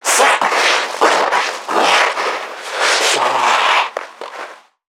NPC_Creatures_Vocalisations_Infected [123].wav